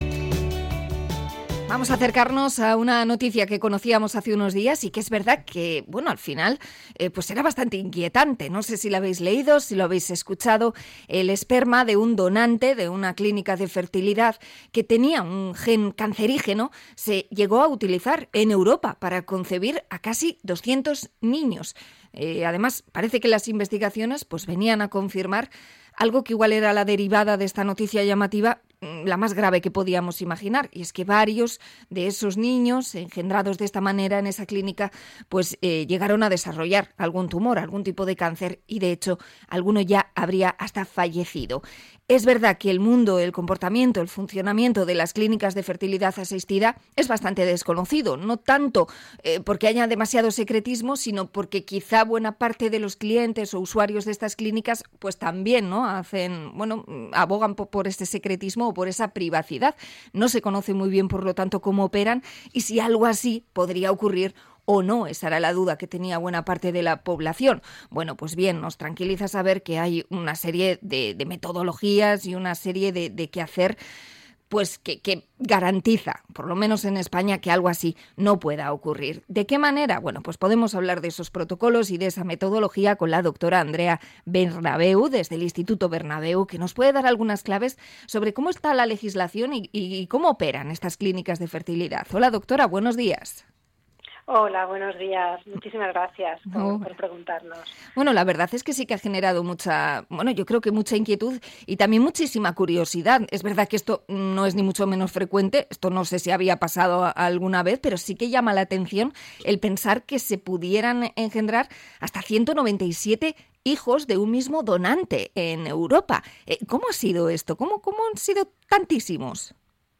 Entrevista a clínica de fertilidad por el donante con gen cancerígeno